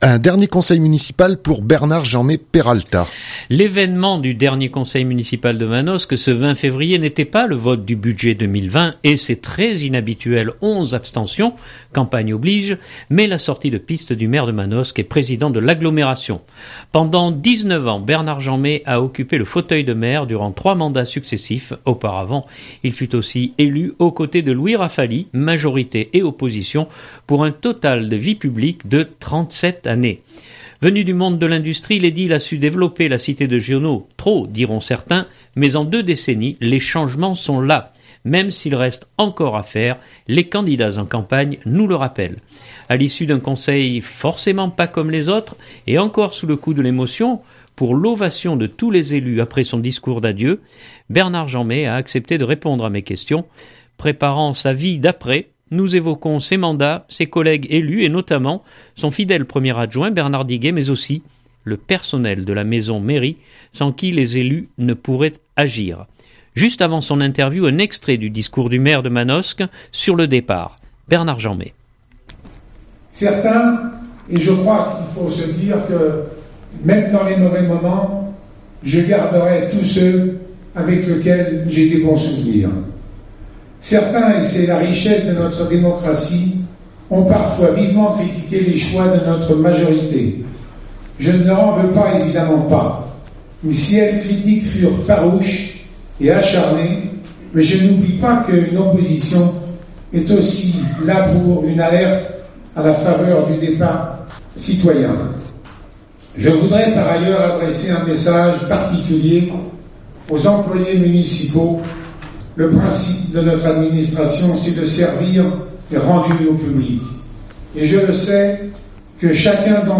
A l’issue d’un conseil forcément pas comme les autres, et encore sous le coup de l’émotion pour l’ovation de tous les élus après son discours d’adieu, Bernard Jeanmet a accepté de répondre à mes questions. Préparant sa vie d’après, nous évoquons ses mandats, ses collègues élus et notamment son fidèle premier adjoint Bernard Diguet mais aussi le personnel de la maison Mairie sans qui les élus ne pourraient agir. Juste avant son interview un extrait du discours du maire de Manosque sur le départ.